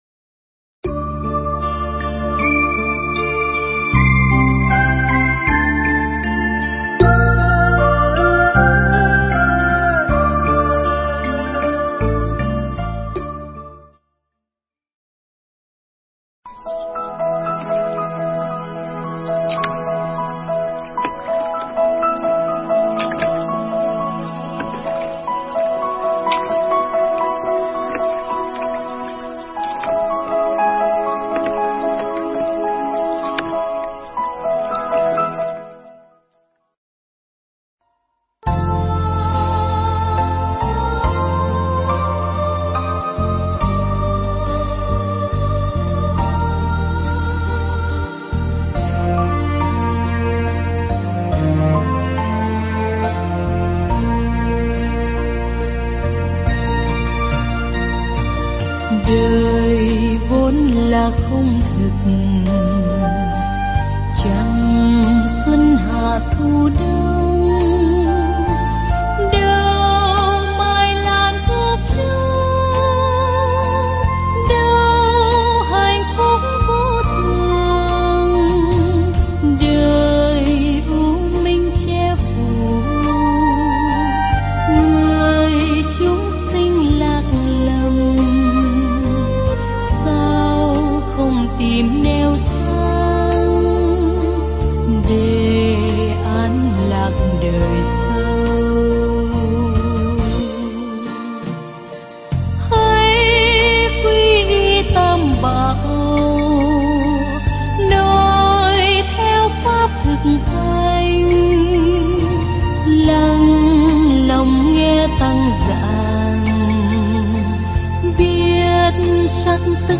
Nghe Mp3 thuyết pháp Những Gì Còn Lại